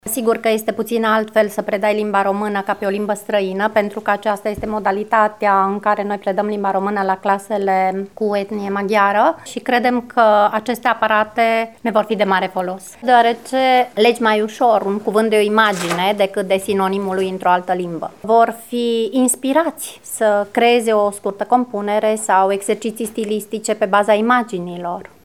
Profesoara de limba română